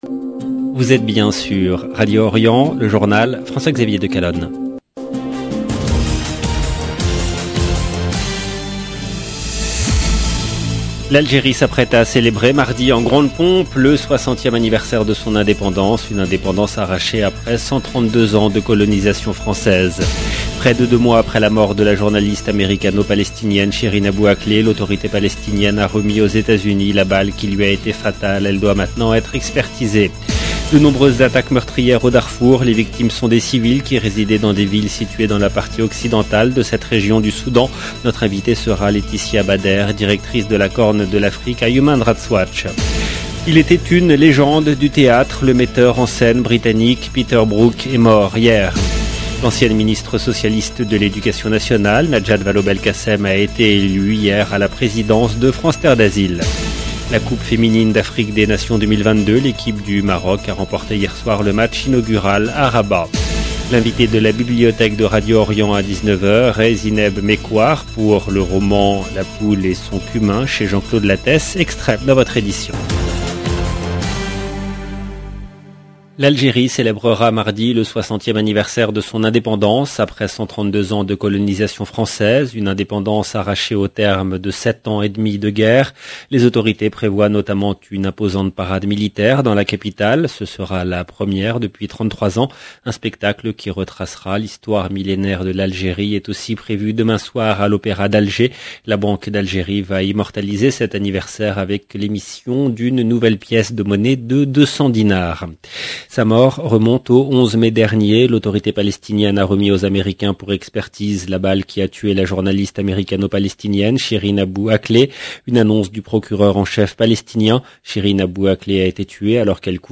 LE JOURNAL DU SOIR EN LANGUE FRANCAISE DU 3/7/2022